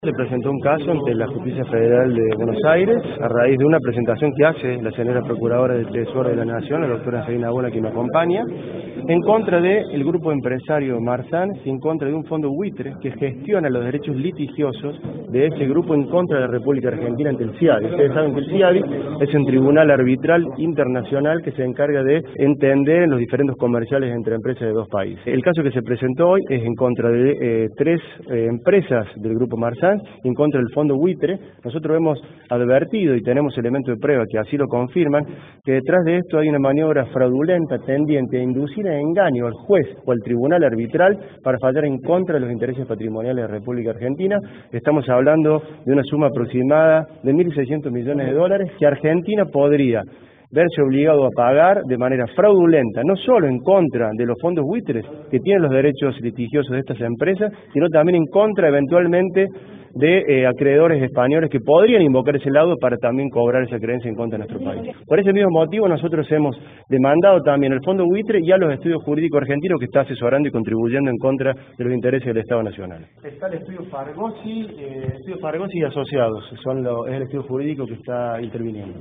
Compartimos el audio con las declaraciones de Carlos Gonella titular de la PROCELAC y la nota publicada por este medio en el año 2013 anticipando la maniobra y el negocio de Burford Capital con Marsans.